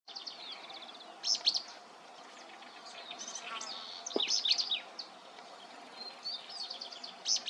Uí-pi (Synallaxis albescens)
Fase da vida: Adulto
Localidade ou área protegida: Cercanias de la Reserva Provincial Parque Luro
Condição: Selvagem
Certeza: Gravado Vocal
075-pijui-cola-parda-canto.mp3